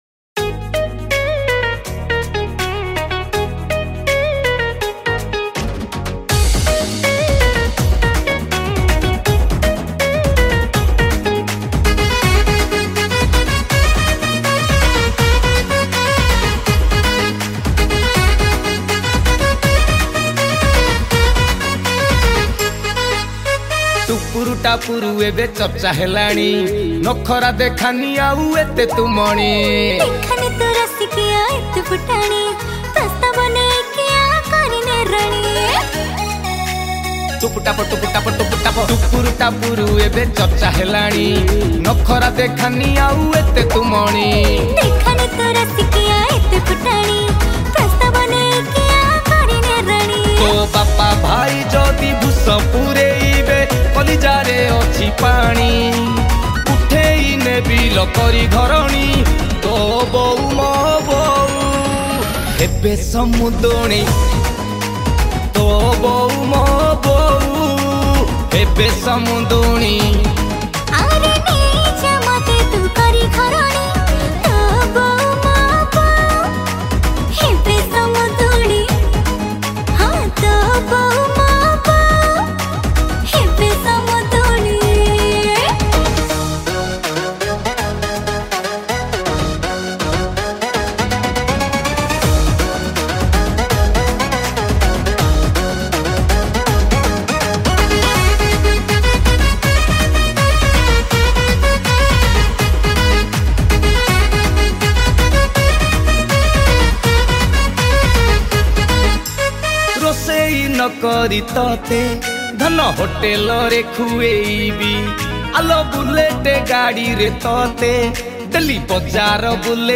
Song Studio Version